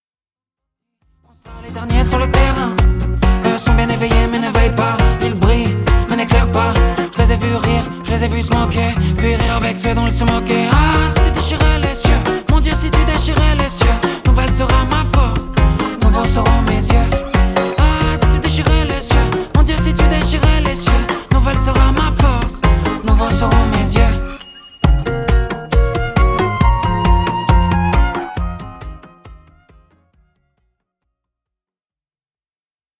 reggae roots